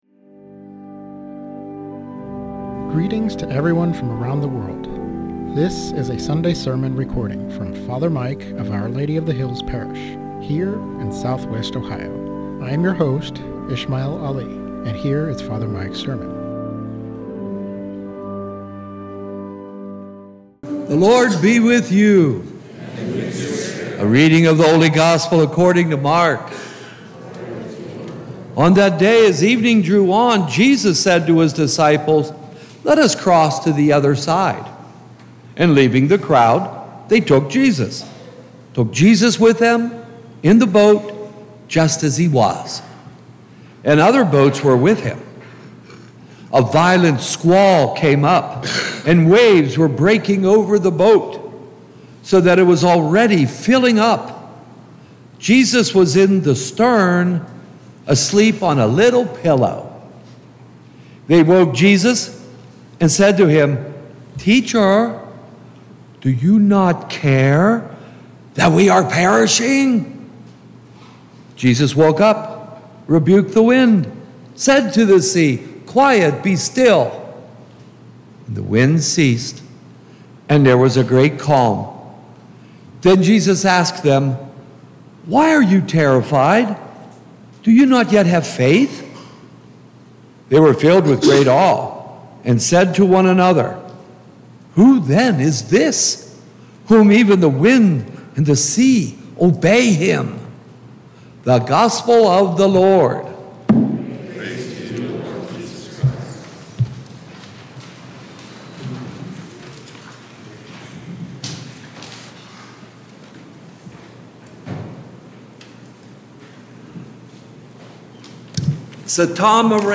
SERMON ON MARK 4:35-41